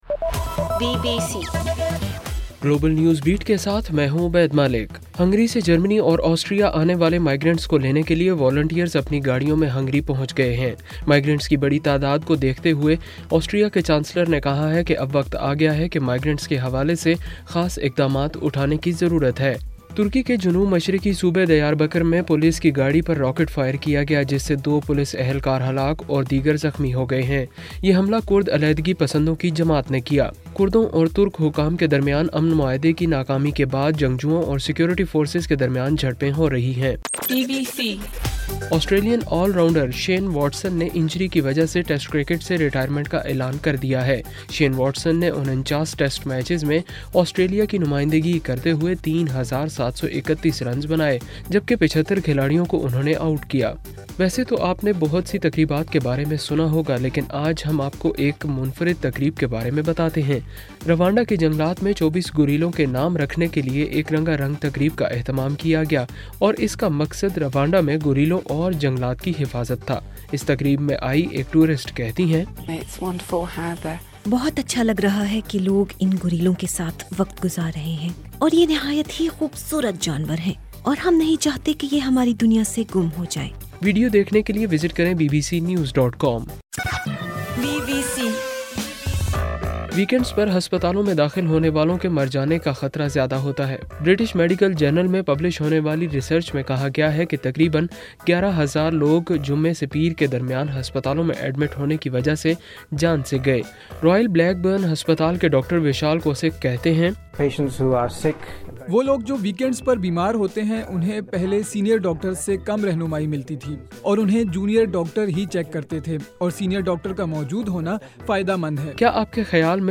ستمبر 7:صبح 1 بجے کا گلوبل نیوز بیٹ بُلیٹن